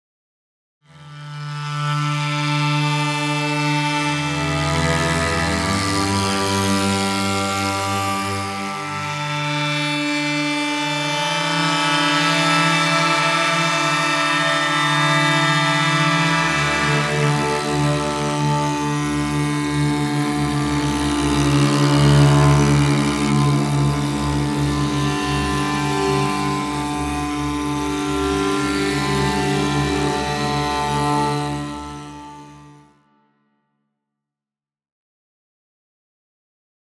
There are lovely scratchy bowed sounds that percolate with sizzling harmonics.
Below are a few sound examples recorded without any post-processing effects.
Using Legato and Dissonant sources
legato-dissonant.mp3